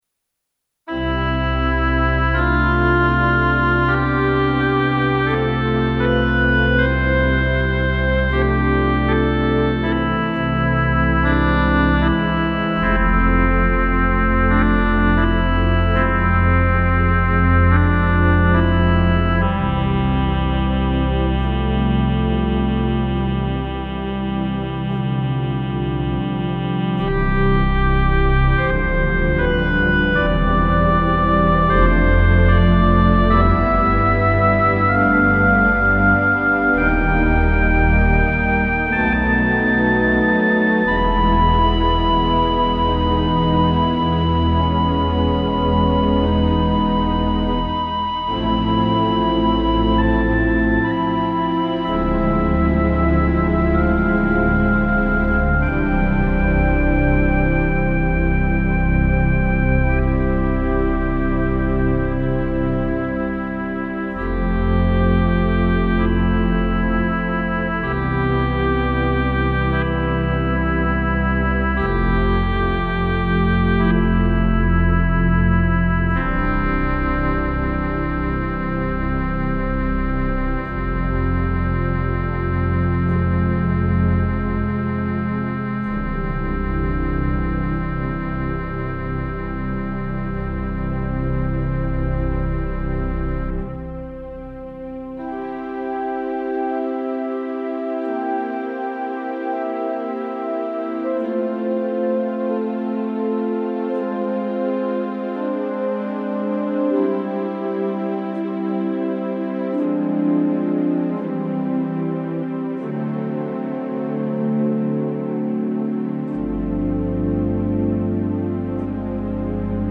Rodgers 205 Hybrid Organ (circa 1978)